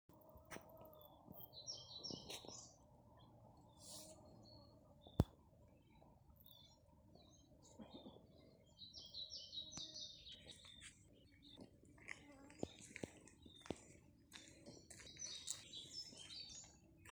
Pied Flycatcher, Ficedula hypoleuca
StatusSinging male in breeding season
Notes Dzied muižas parka kokos